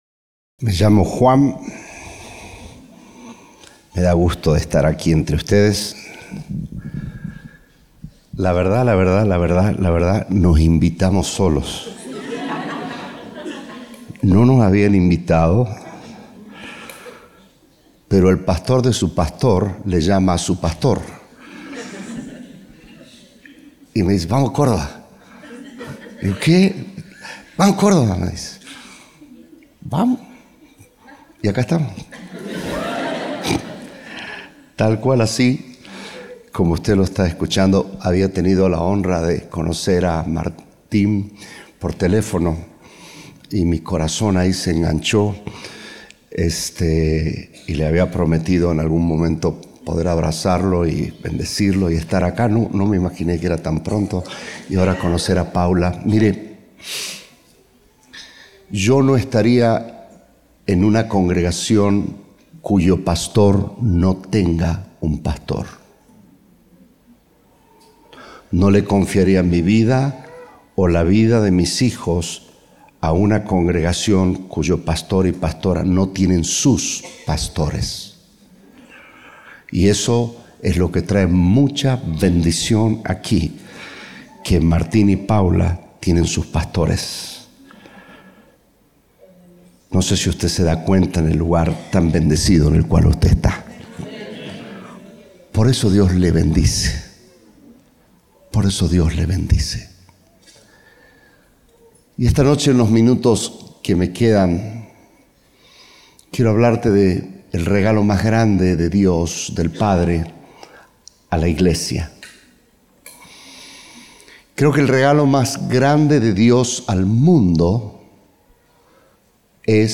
Compartimos el mensaje del Domingo 31 de Agosto de 2025 (20:00 hs)